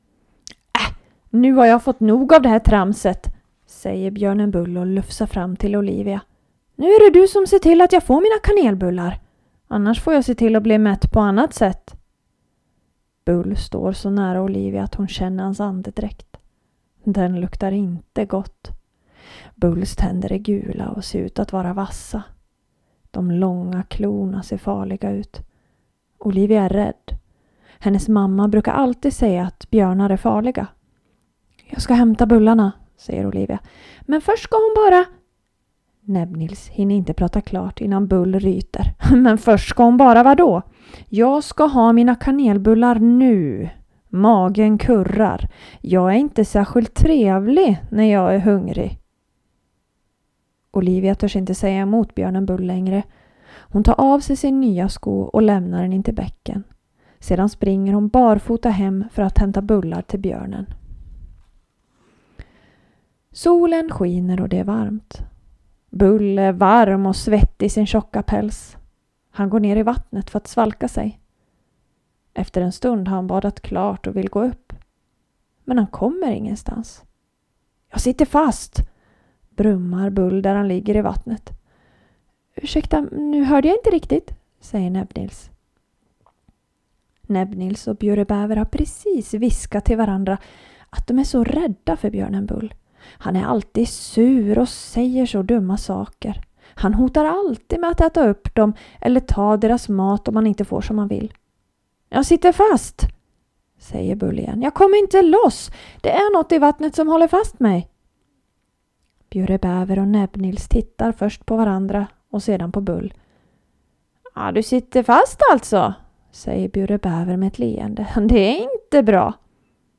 Utöver detta kan du lyssna på sagan – både på standardsvenska och bjursmål.
Station 7 – standardsvenska